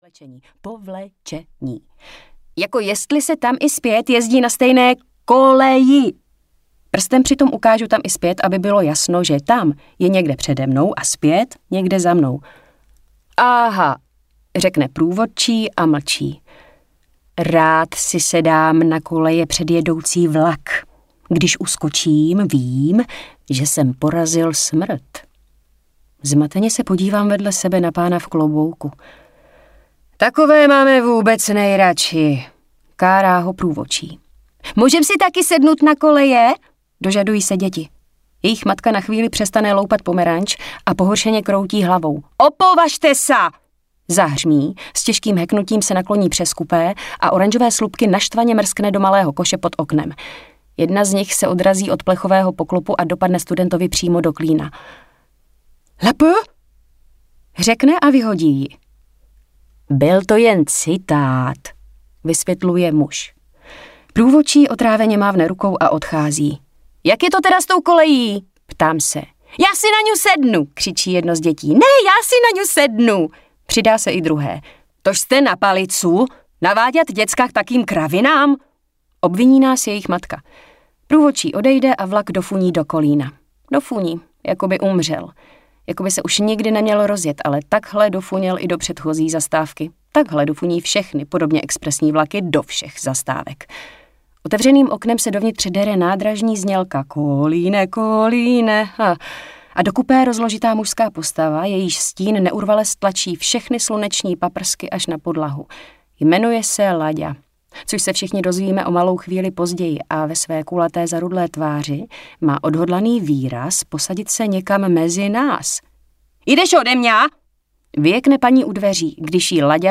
Veselí audiokniha
Ukázka z knihy